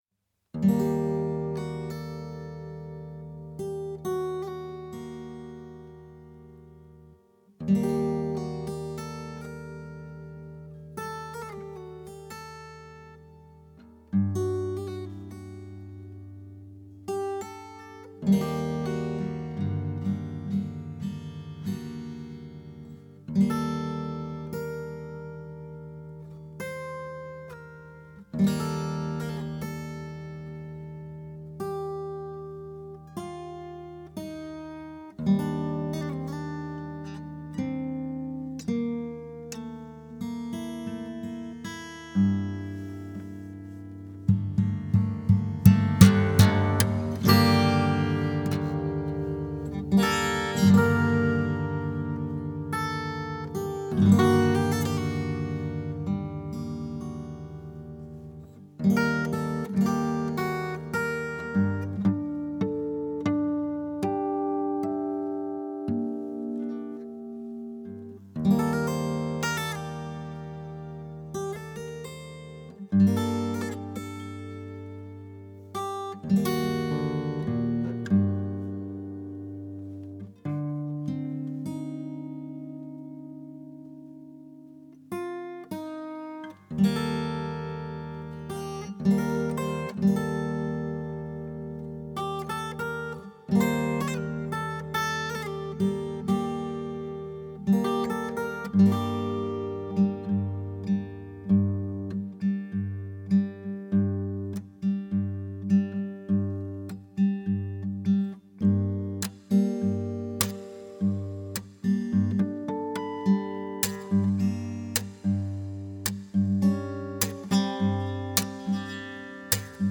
'Solo guitar pandiatonic free improvisation'.
acoustic guitar
is an intimate acoustic guitar session